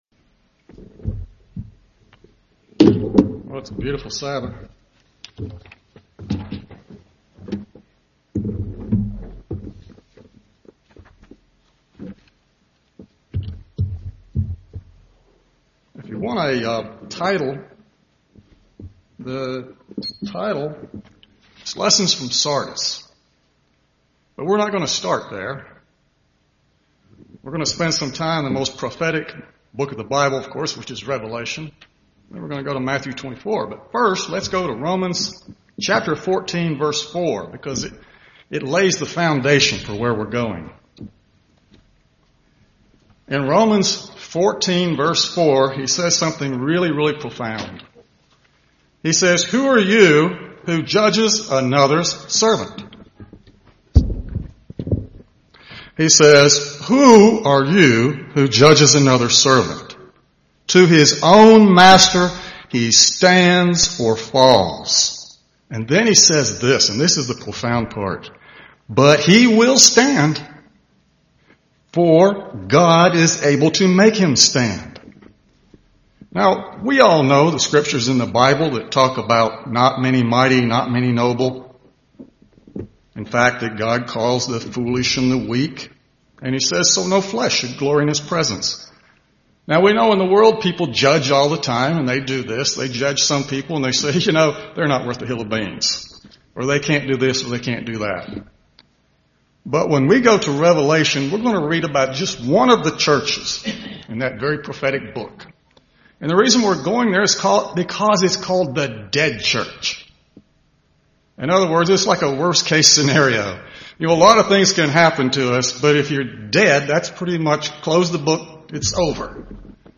Given in Birmingham, AL Gadsden, AL
UCG Sermon Studying the bible?